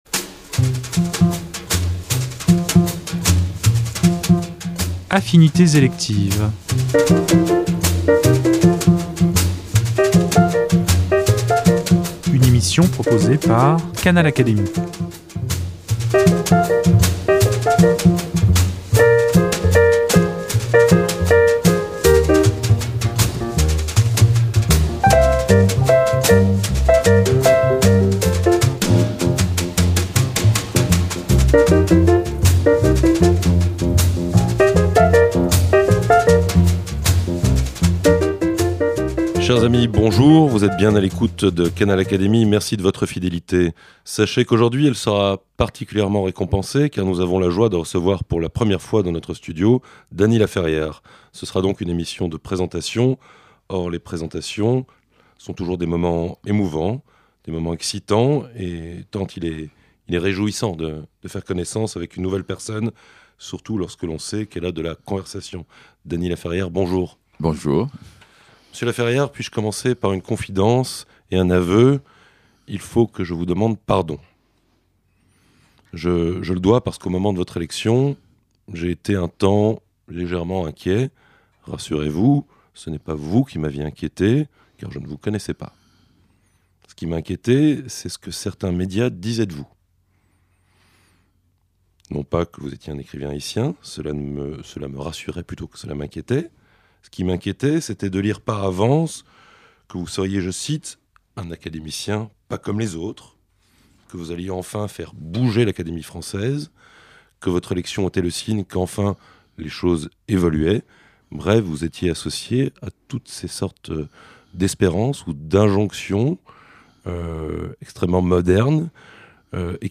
A cette occasion, nous l’avons reçu dans nos studios afin de faire plus ample connaissance.